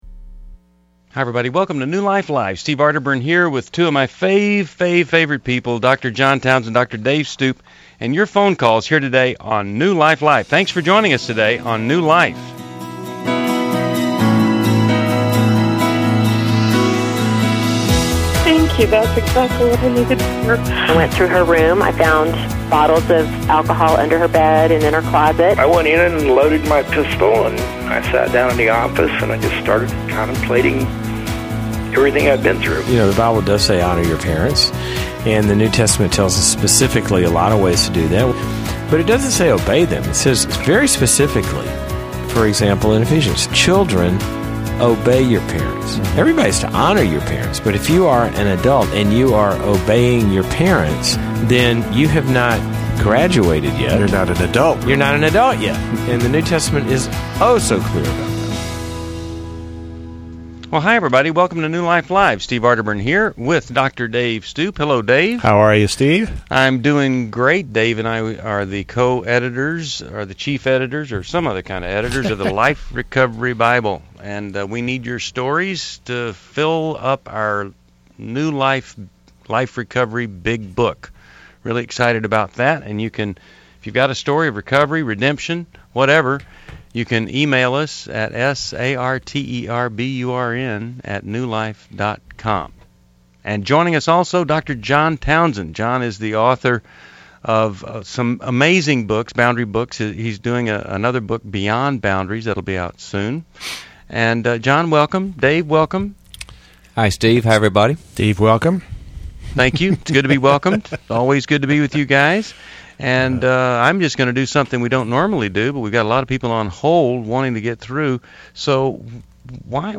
Explore relationship struggles on New Life Live: September 22, 2011, as hosts tackle issues of forgiveness, infidelity, and managing anxiety in marriage.